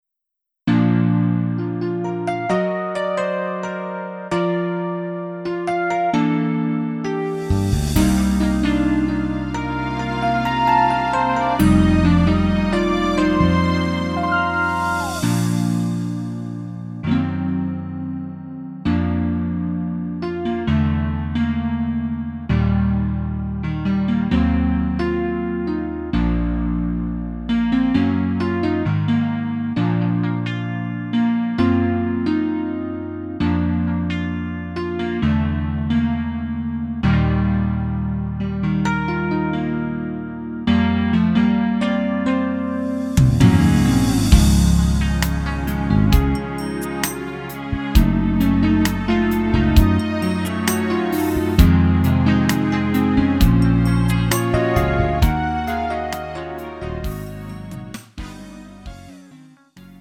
음정 -1키 4:15
장르 가요 구분 Lite MR